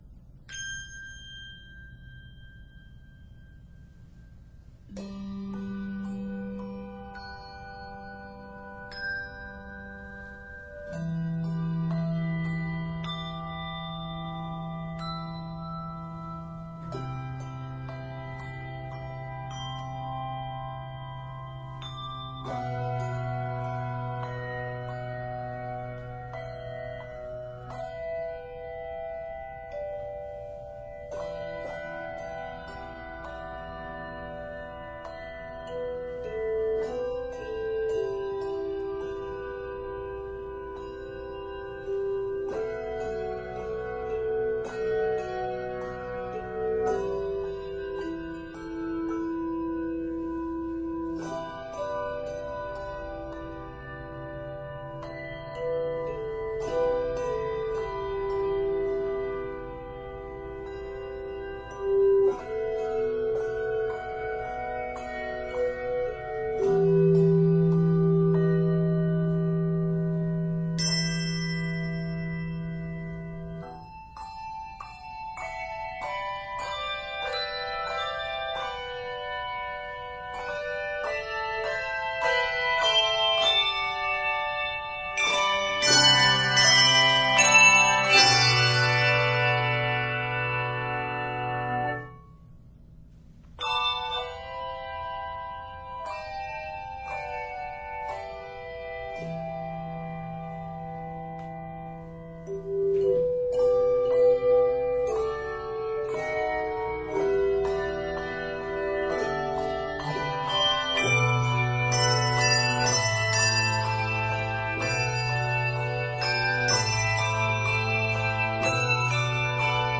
is arranged in e minor and is 56 measures.